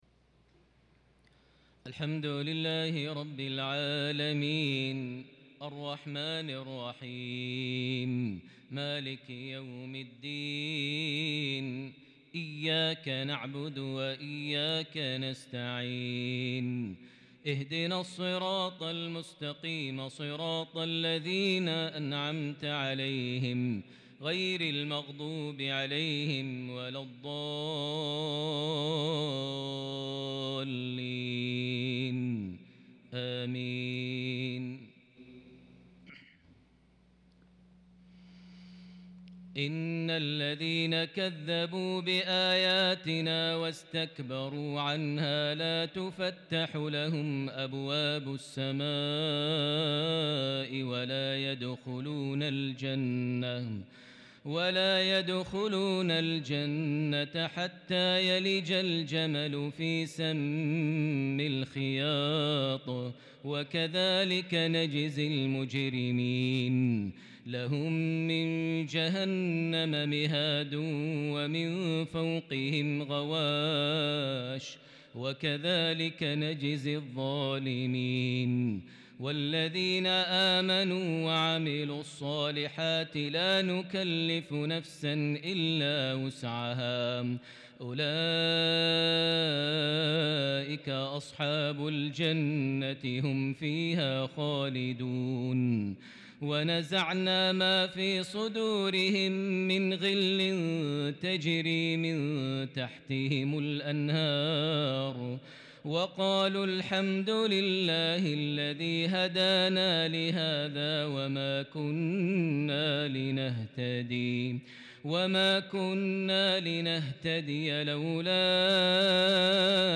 صلاة العشاء من سورة الأعراف |الاثنين 29 محرم 1443هـ | lsha 6-9-2021 prayer from Surah Al-Araf 40-53 > 1443 🕋 > الفروض - تلاوات الحرمين